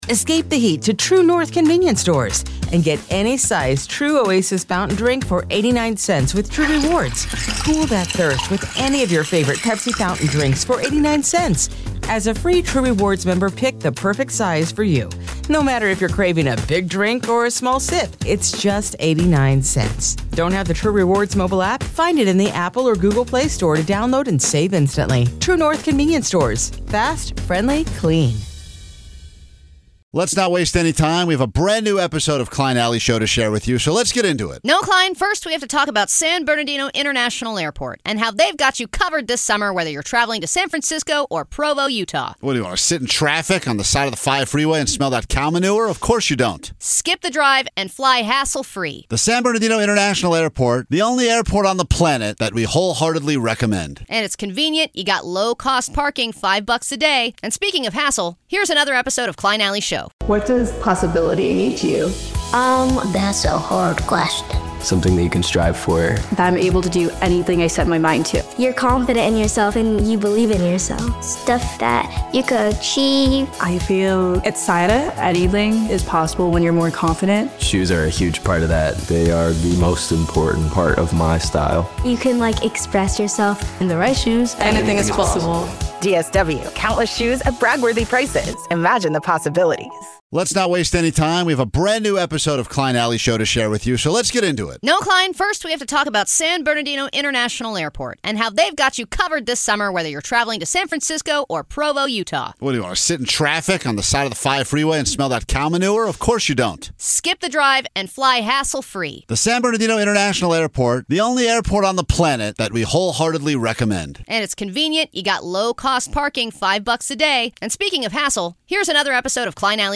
the show is known for its raw, offbeat style, offering a mix of sarcastic banter, candid interviews, and an unfiltered take on everything from culture to the chaos of everyday life.